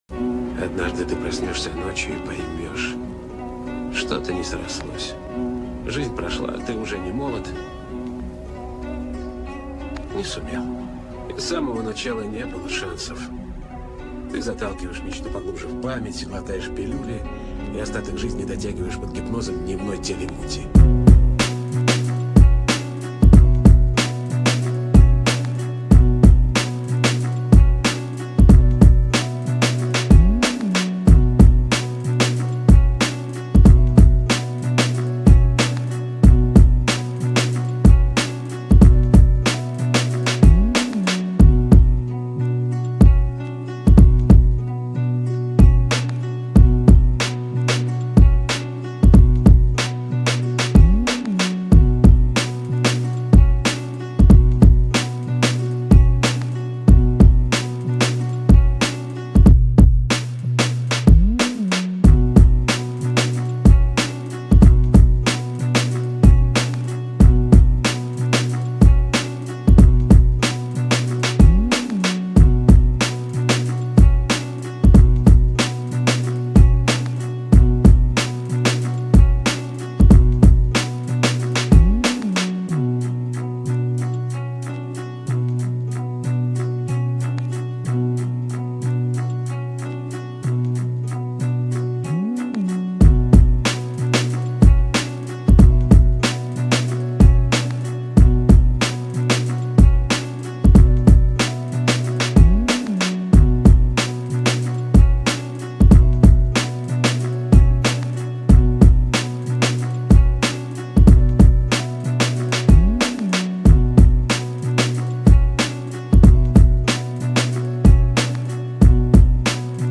• Category: Underground